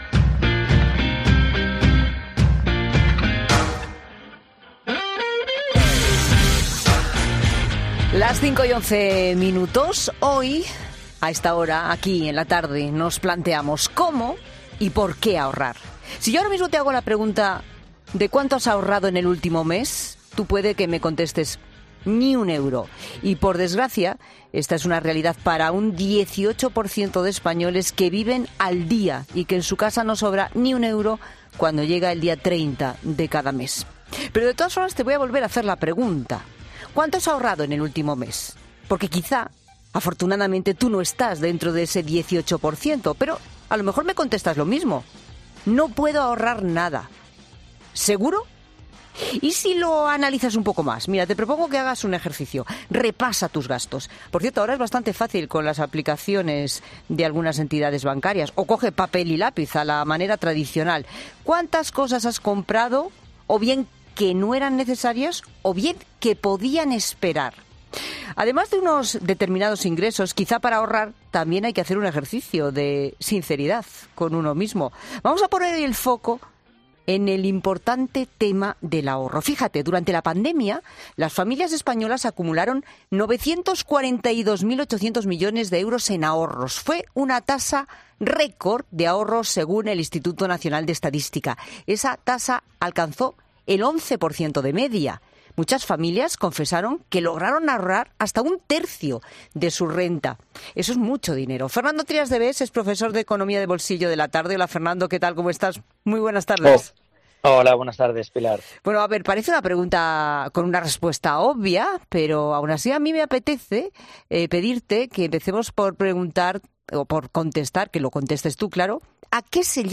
Puedes escuchar la entrevista completa aquí en La Tarde de COPE